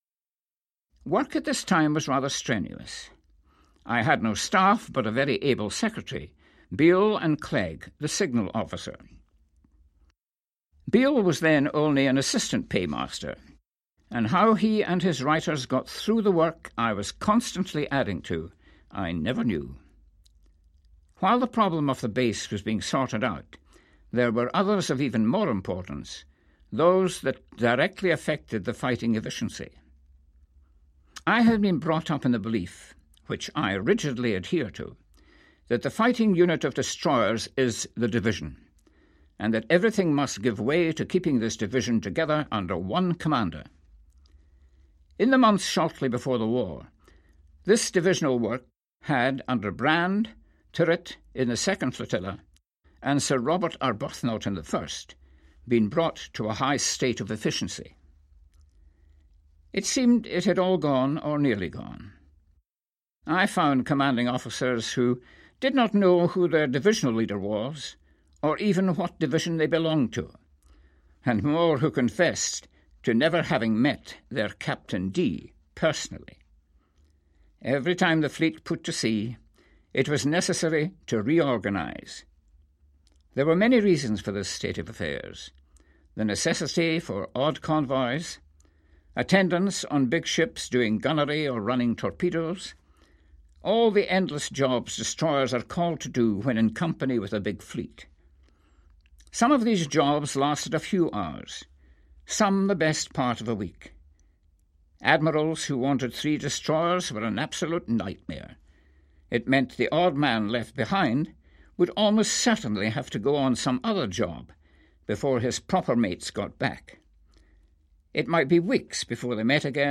Here is his account of life with the Grand Fleet, and the last act of the war,